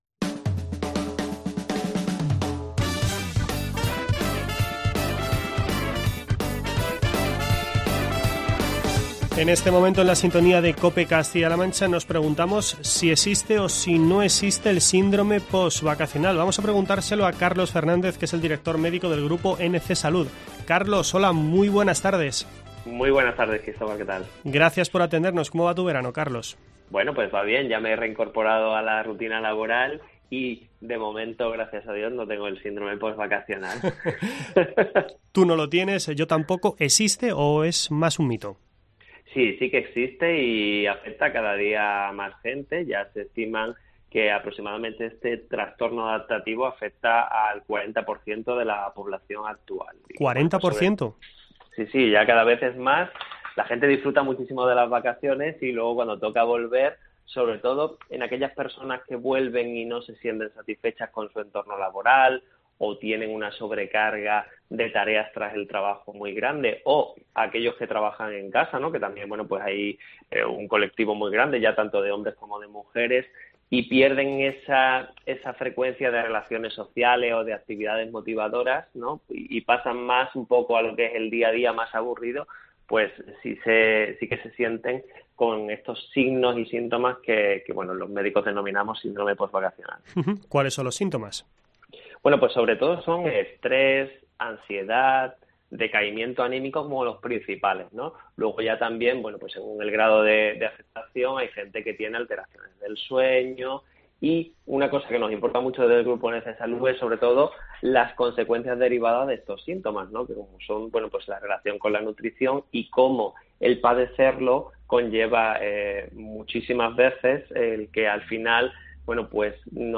Charlamos sobre este asunto